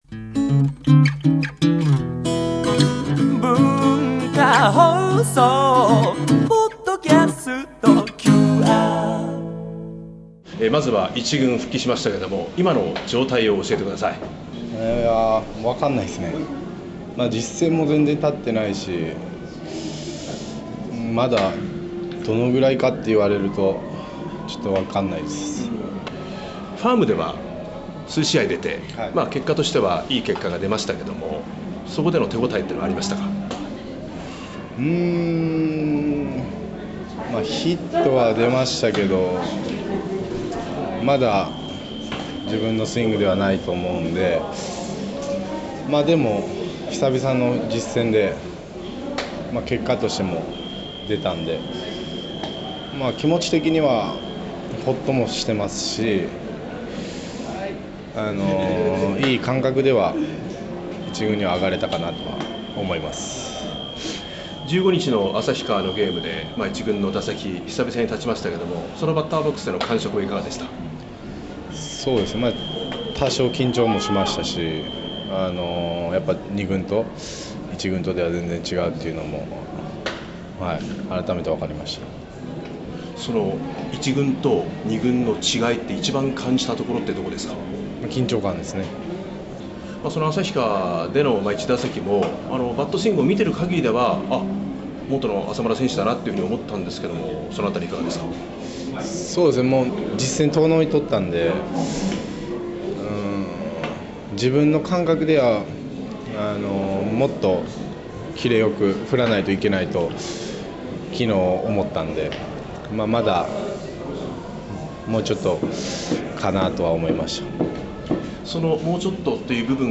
２３歳頼れる若獅子の声を定期的にお届けしています。